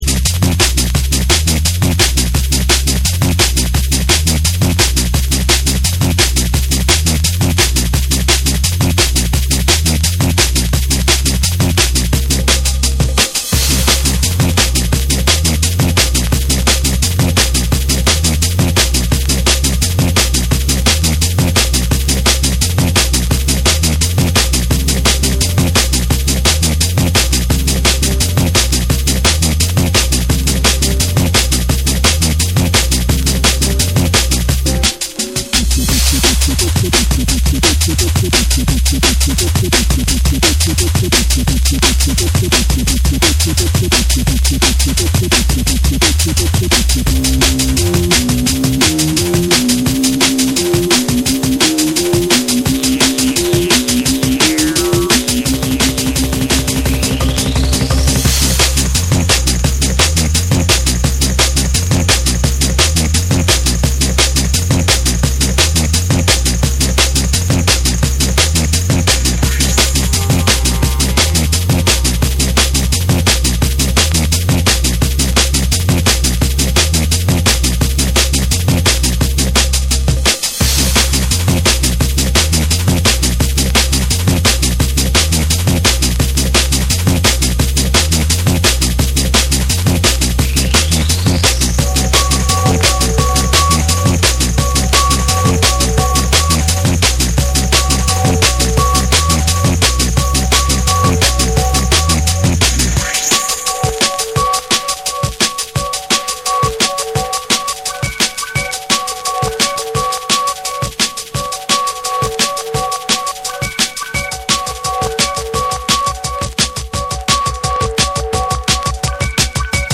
攻撃的なブレイクビーツに、ハードステップなベースラインが炸裂する、フロア直撃の即戦力ドラムンベース・チューンを収録！